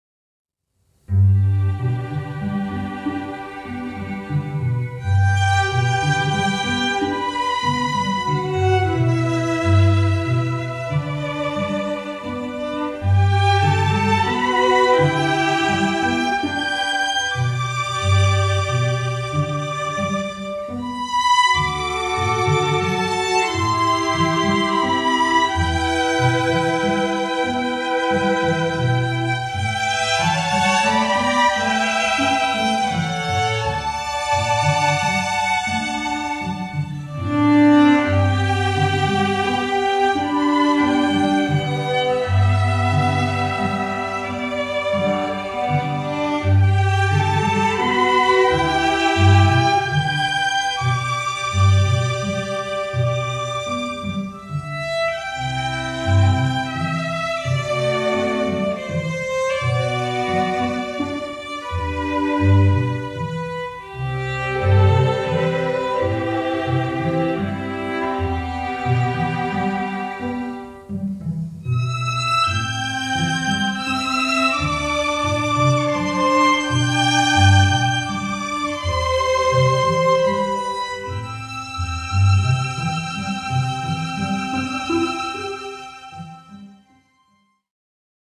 Voicing: String Quartet